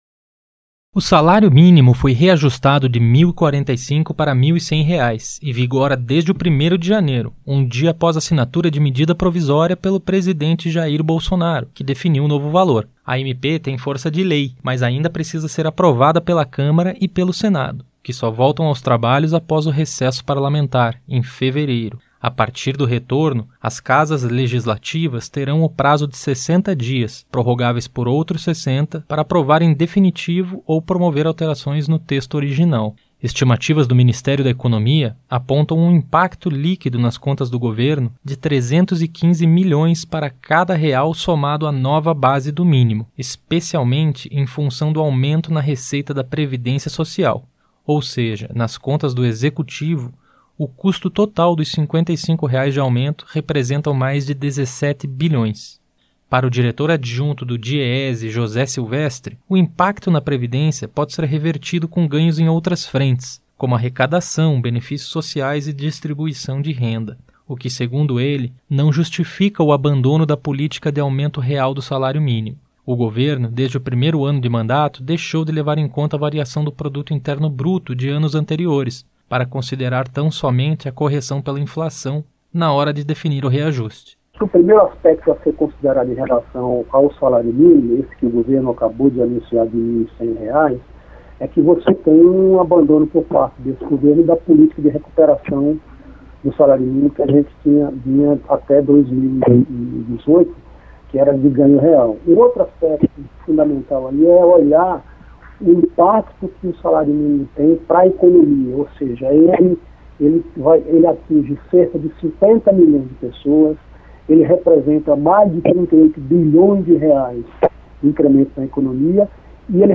Correção de salário mínimo pela inflação, conforme Medida Provisória do presidente da República, já vigora e não leva em conta média do produto interno bruto de anos interiores. Os detalhes na reportagem.